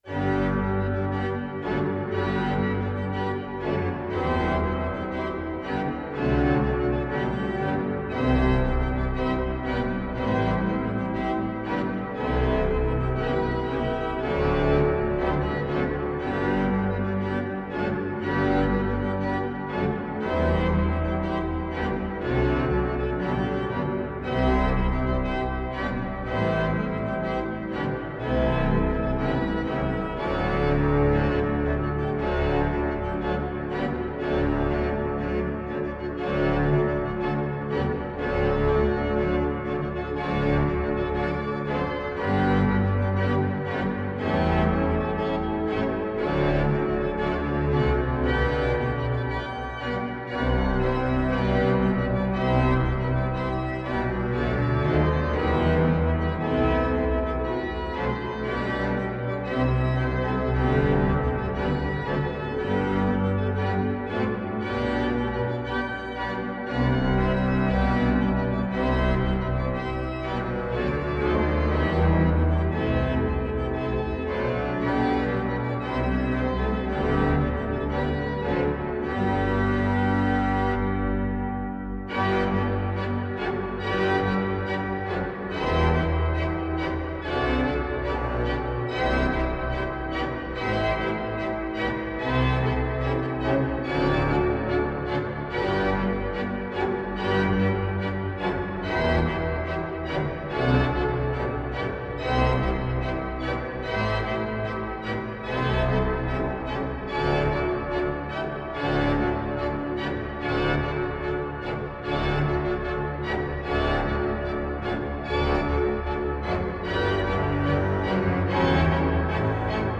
Il peut être joué avec grandeur et une certaine audace.
• Mesures 29 à 64 : vous pouvez déjà ajouter quelques jeux.